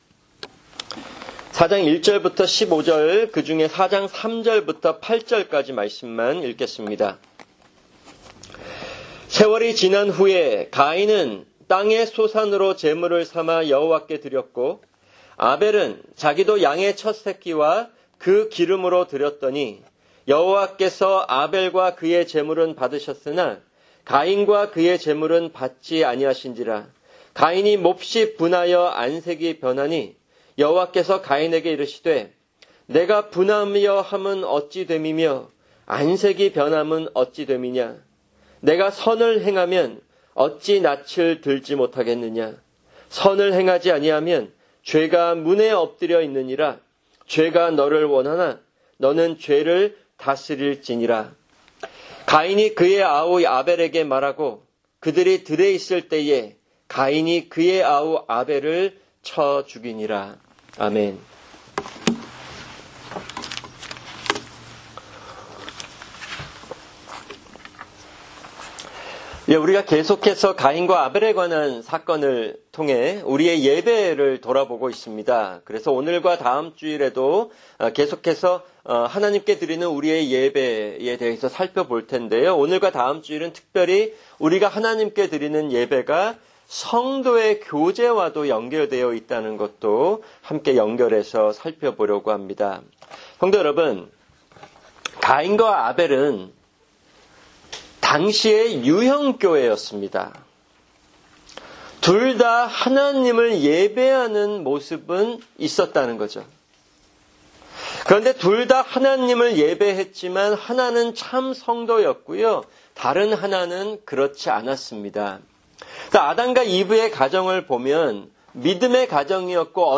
[주일 설교] 창세기 4:1-15(2)